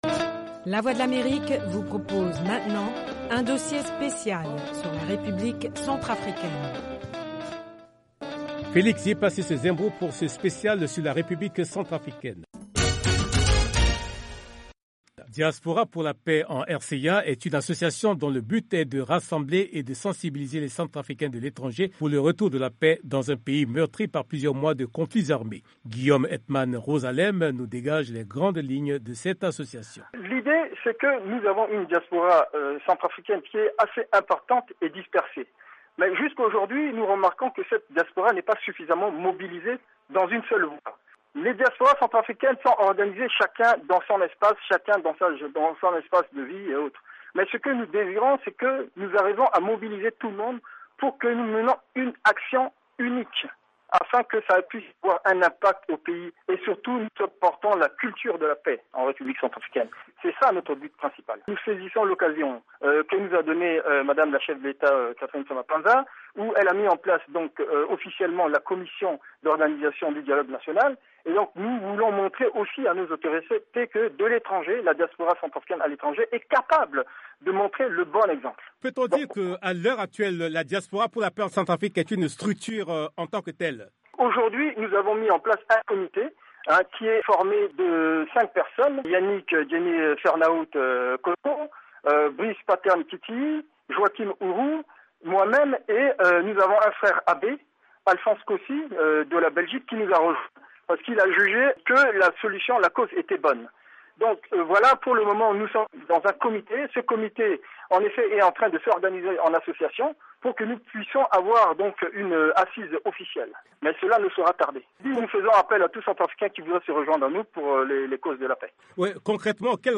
RCA: Reportage Special
CAR - Special RCA Reportage Special Monday-Friday